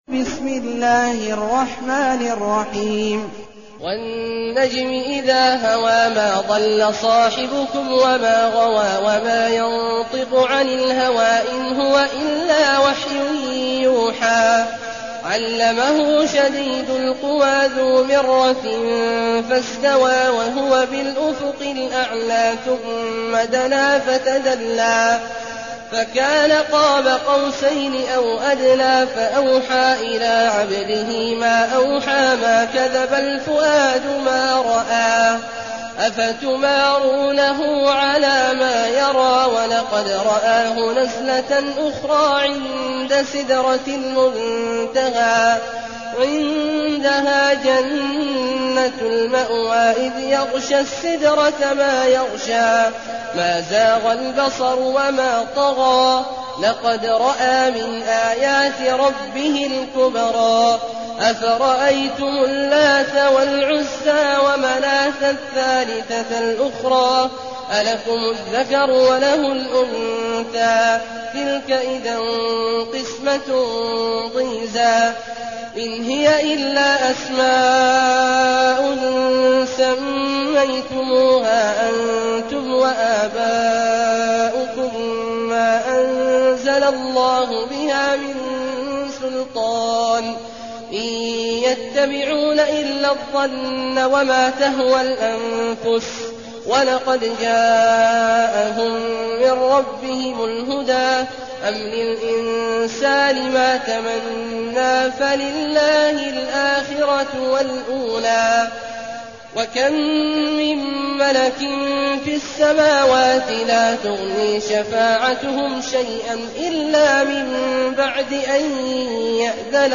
المكان: المسجد الحرام الشيخ: عبد الله عواد الجهني عبد الله عواد الجهني النجم